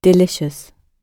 delicious-gb.mp3